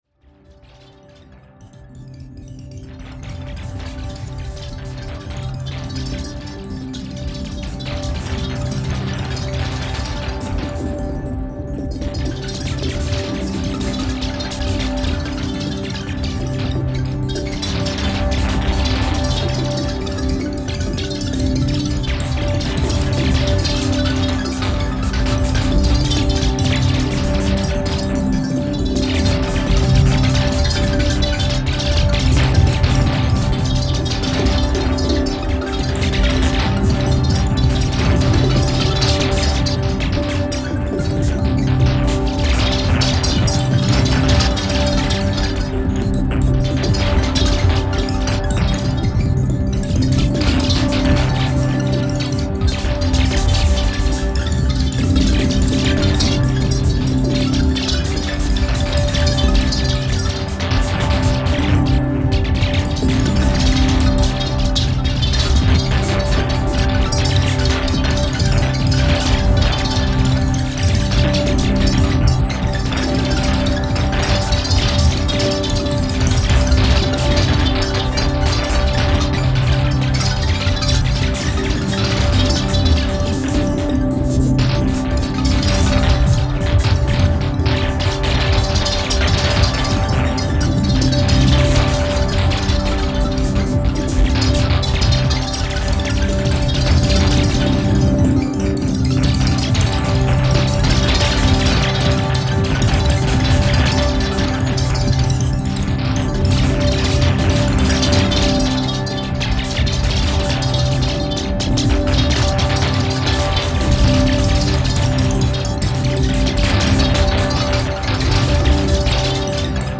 本作は久々のコンピュータのみによるオーケストレーション作品。
私にはアーバンで近未来的に聴こえる一方で、人類が誕生する以前の超太古の原始の海に降り注ぐ雨音のようにも聴こえます。